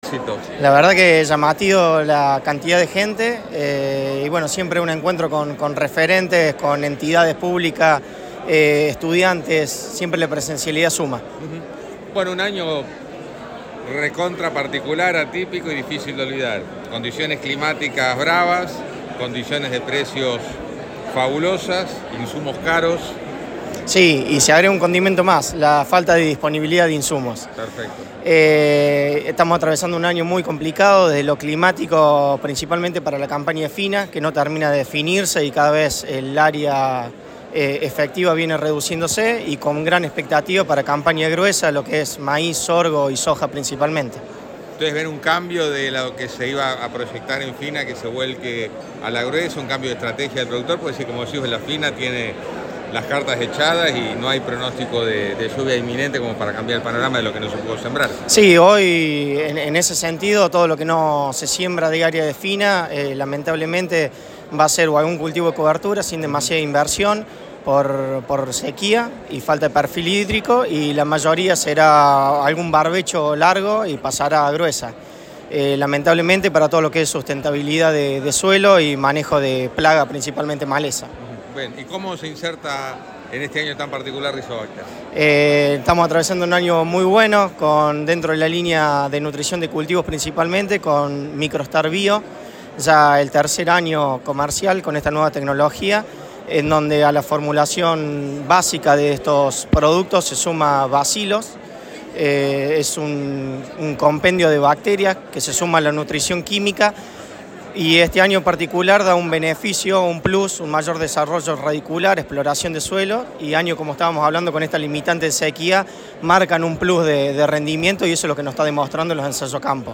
Participante habitual de todo tipo de eventos del sector Rizobacter se hizo presente en el Congreso Maizar de Parque Norte.